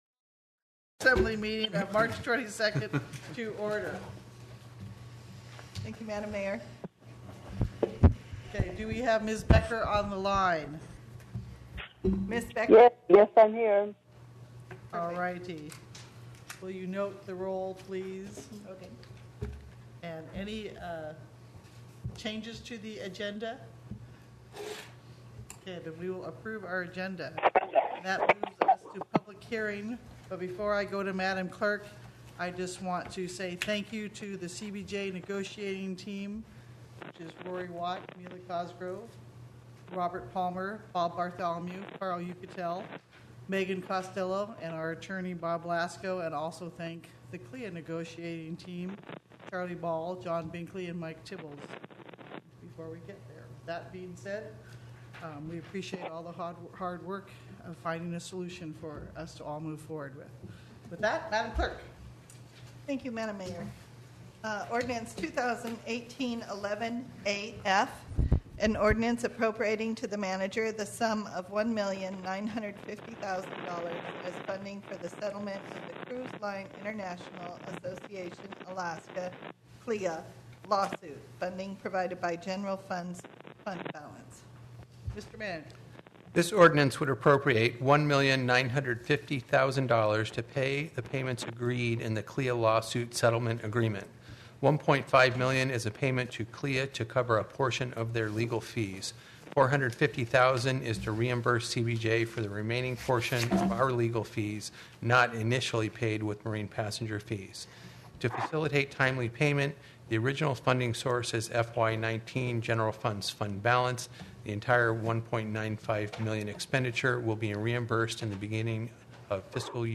CBJ Assembly Special Meeting – March 22, 2019
The City and Borough of Juneau Assembly’s special meeting on March 22, 2019.